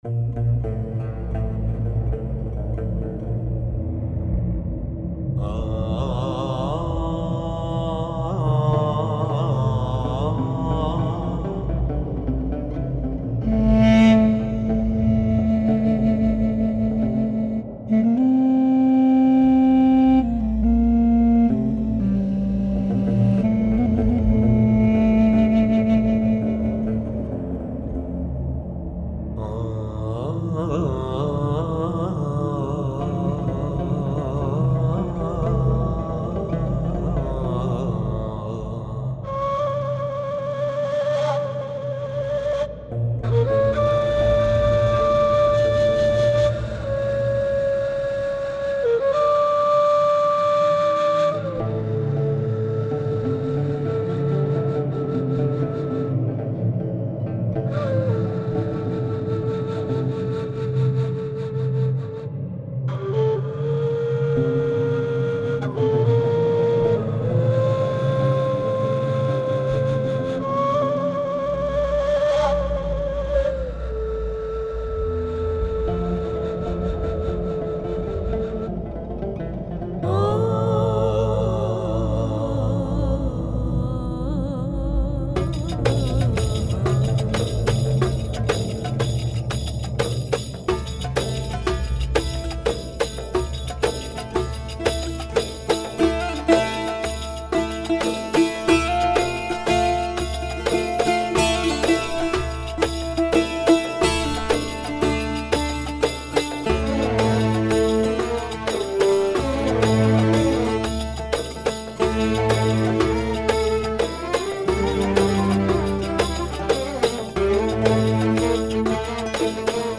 I am going on exploring soundscapes, inspired by some masterpieces from hystory of art.